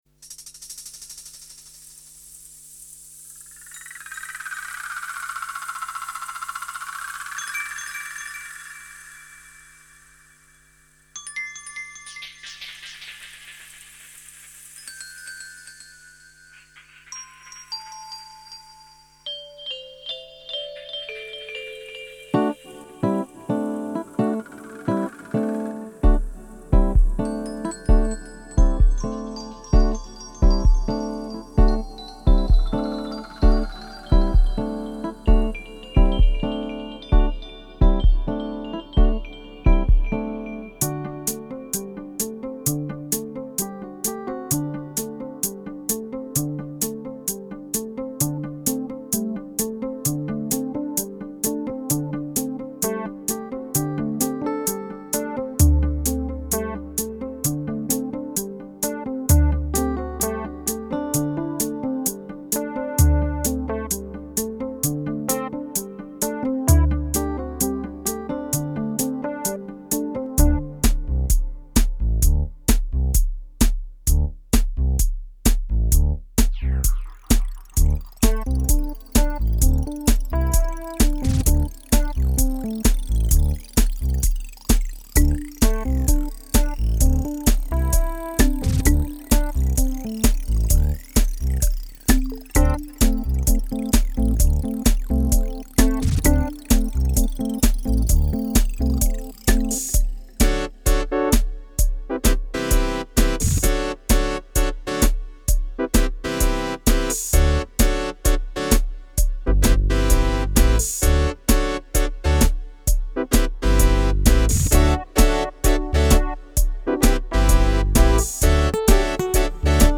Titres compos�s, arrang�s et jou�s par moi-m�me. Clavier QS8 Alesis. Synth� Korg DW8000. Expandeur U20 Roland. S�quenceur Roland MC50. Enregistrement sur platine CD.